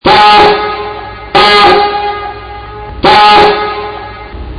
Fire Whistle
Diaphone_horn.mp3